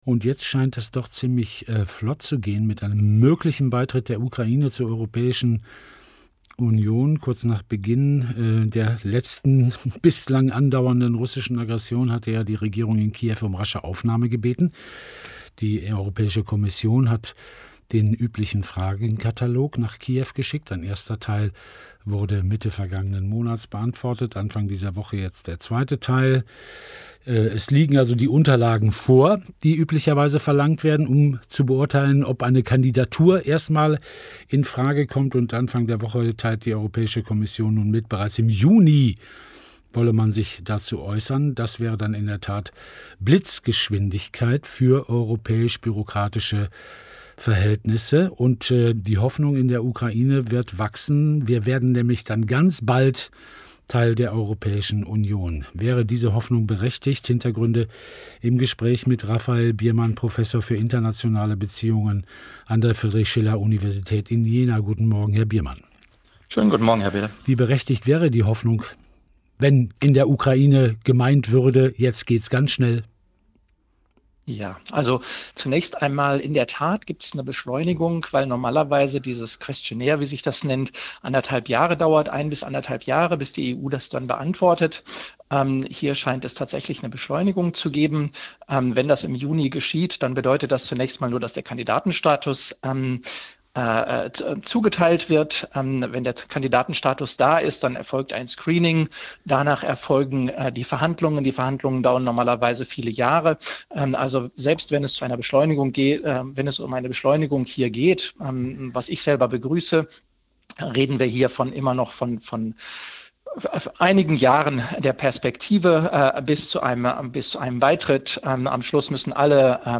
Click heremp3, 23 mb · de to hear the whole interview in german language.